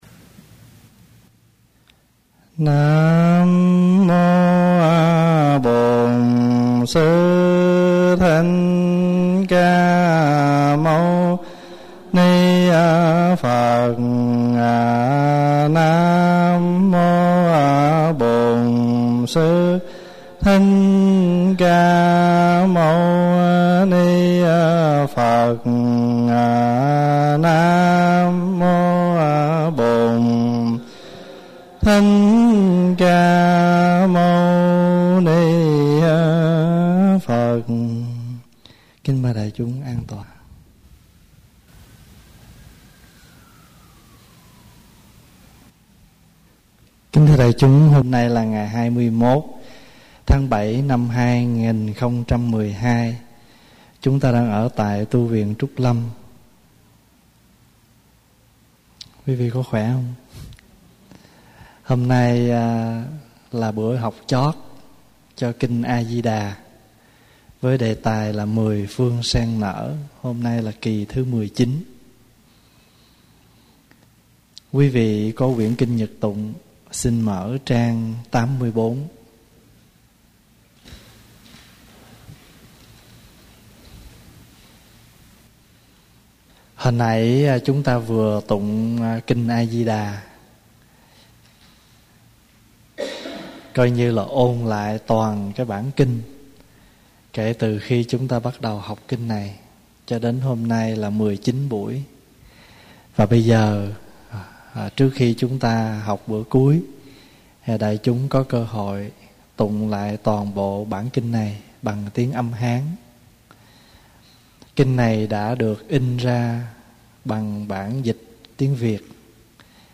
CDs - Mười Phương Sen Nở (chú giải Kinh A Di Đà) - Các Băng Giảng CD - Tu Viện Trúc Lâm - Viện Phật Học Edmonton